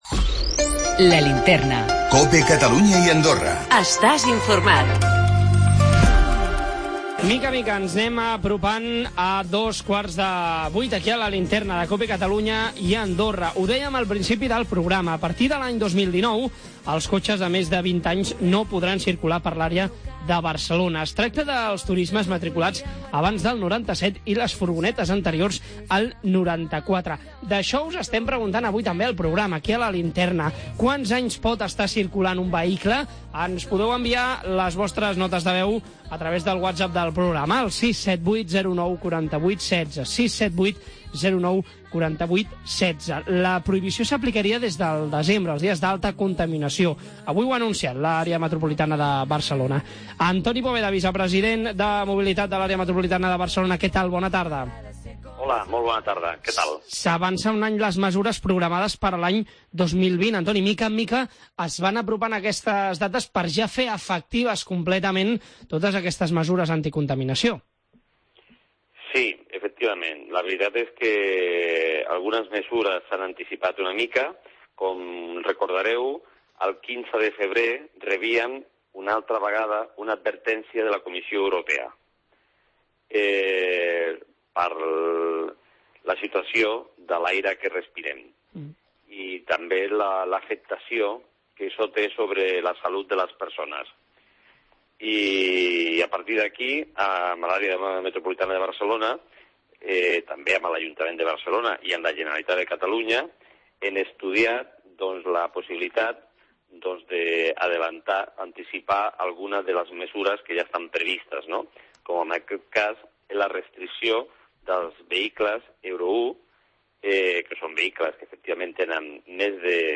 AUDIO: No més cotxes de més de 20 anys.. A partir de 2019! Parlem amb Antoni Poveda, vicepresident d'AMB Mobilitat!